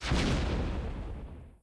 explode-quiet.ogg